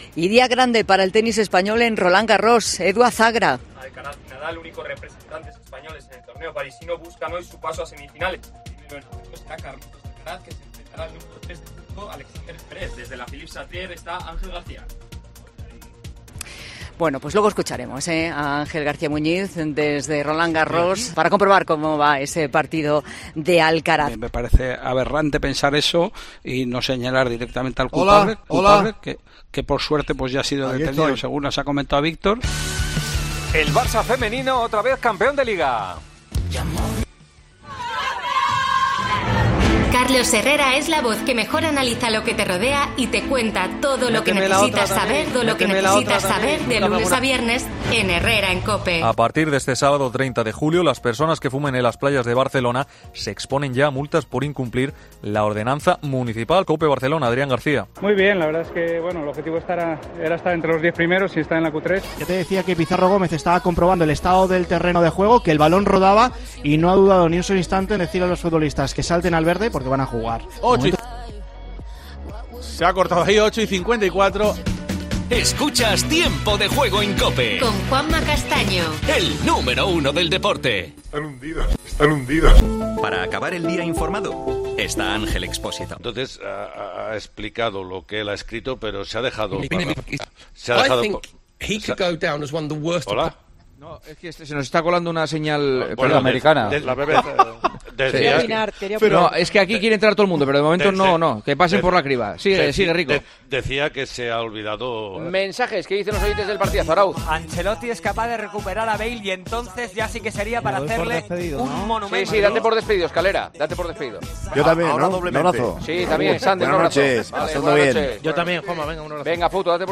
AUDIO: A veces la culpa no es solo del que habla... los fallos técnicos están a la orden del día: Conexiones que no entran, otras que se cortan y se puede...